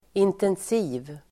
Uttal: [intens'i:v (el. 'in:-)]